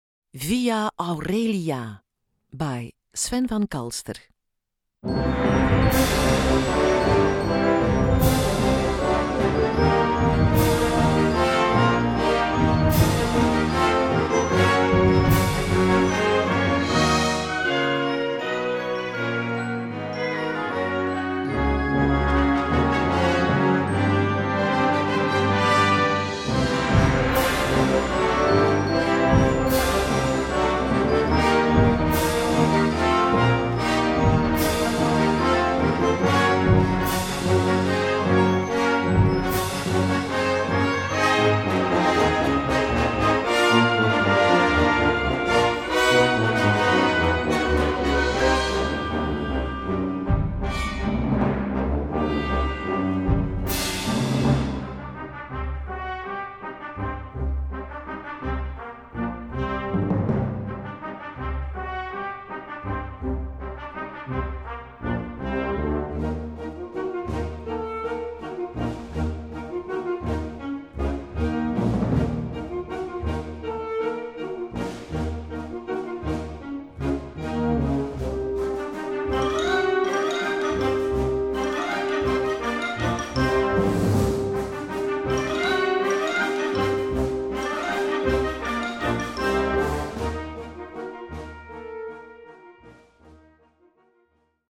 Gattung: Konzertstück
Besetzung: Blasorchester